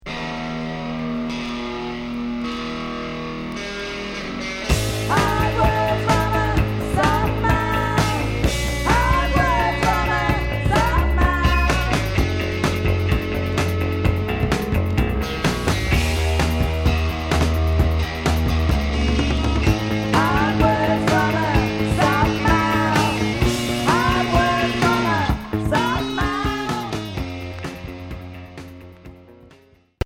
Rock cold wave